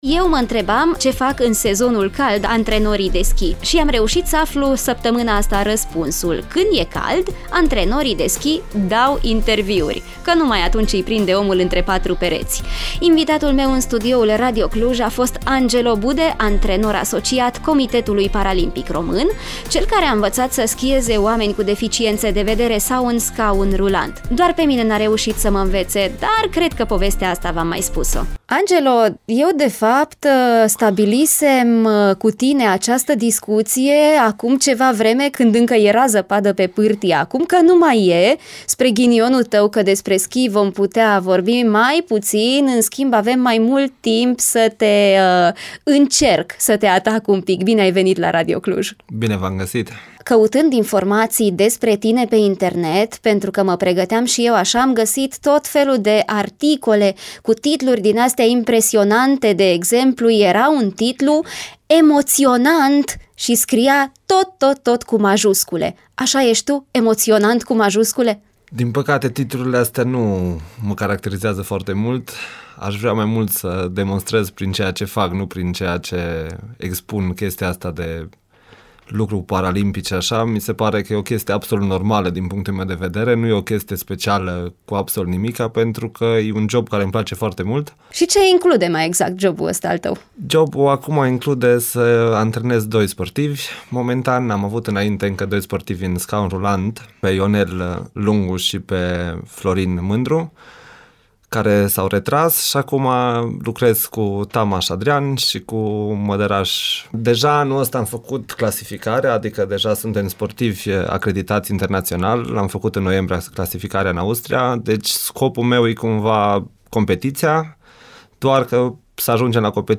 Interviu